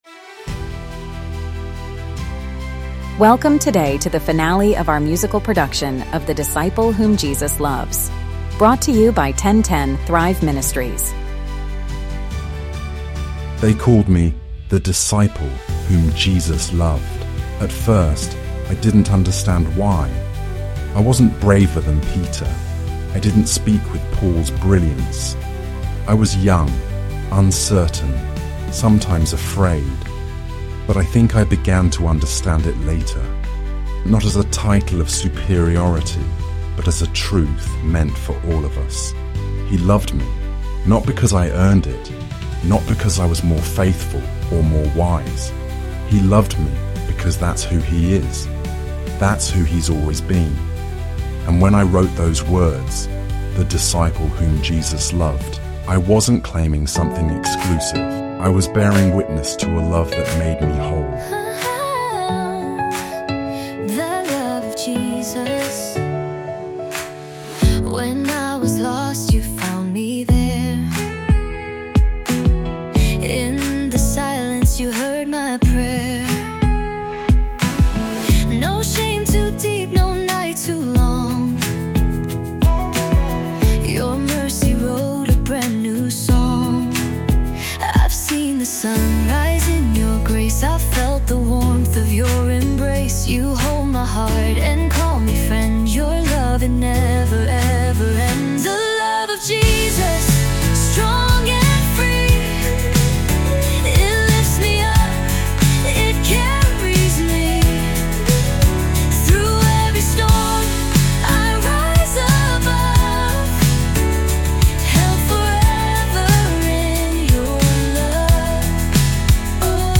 original podcast musical journey